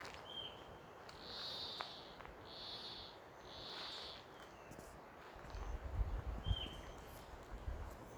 White-tipped Plantcutter (Phytotoma rutila)
Location or protected area: Cañon del Atuel
Condition: Wild
Certainty: Observed, Recorded vocal